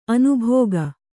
♪ anubhōga